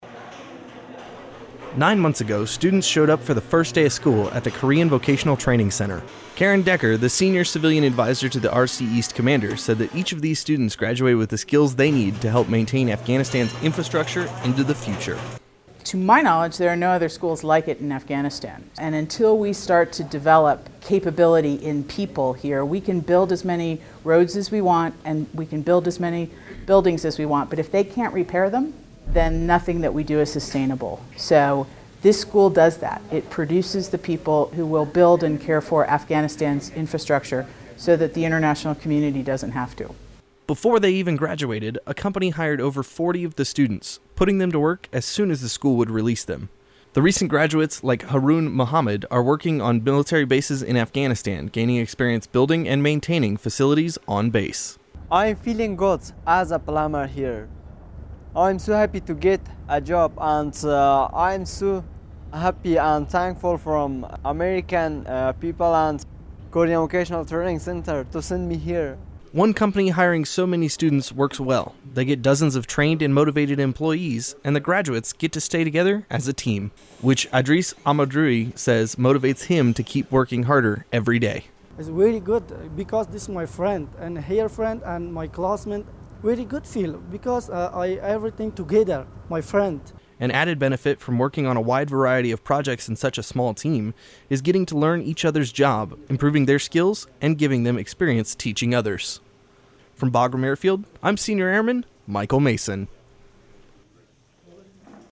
Korean graduation - Radio